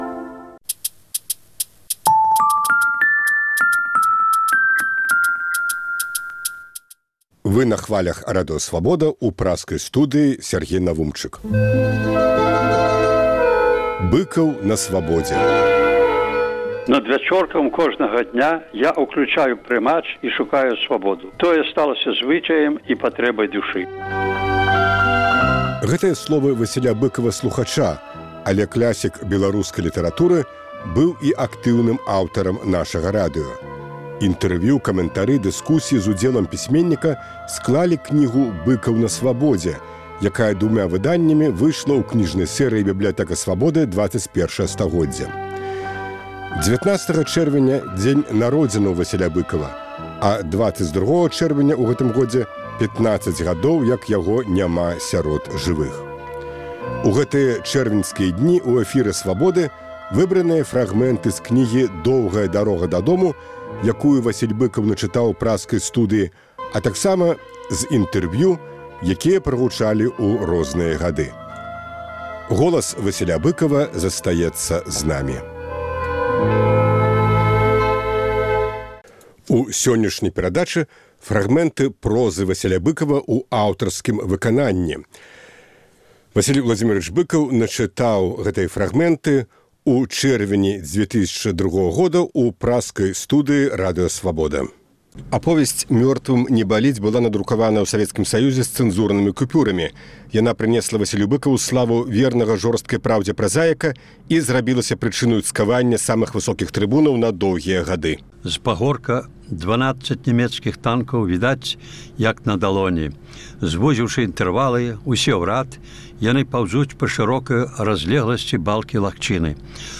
Проза Быкава ў аўтарскім выкананьні
Голас Быкава застаецца з намі. Сёньня – фрагмэнты твораў, якія Васіль Быкаў начытаў у праскай студыі "Свабоды" ў чэрвені 2002 г.